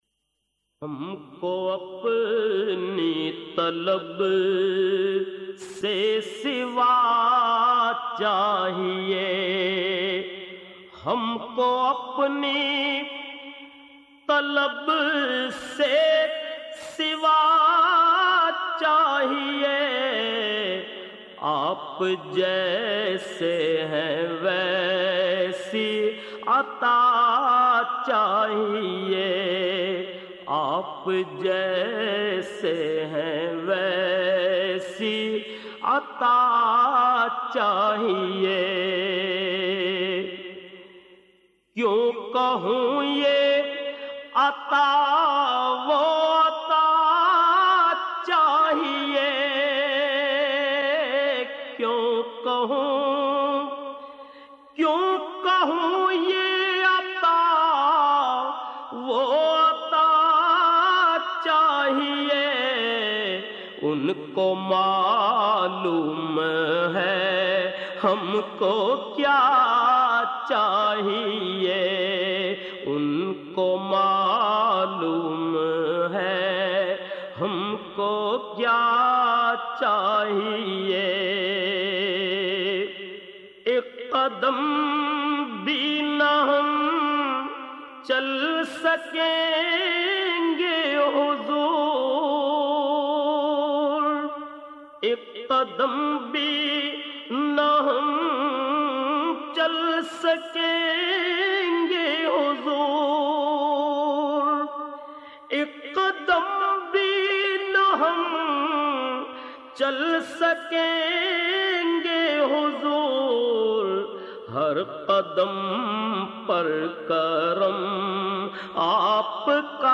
نعت رسول مقبول صلٰی اللہ علیہ وآلہ وسلم